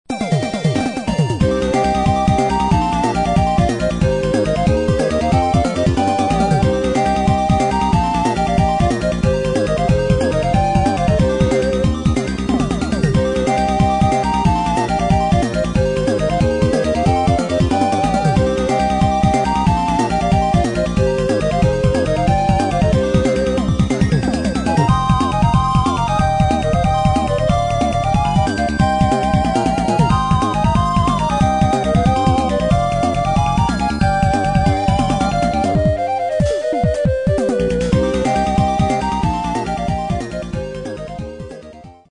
12/16拍子。FM3重PSG3重を想定した6重和音といきたいが、ちょっち厳しいのでそれにドラムを加えた7重。
ベースパターンはoxoooo oxooooで、5/16拍目、10/16拍目をそれぞれオクターヴ上に振る。
ドラムはシンセ系のそれで、タムも同じ音色でいいだろう。
なんか、ふた昔くらい前のシューティングのBGMとして使えそうだ。(笑)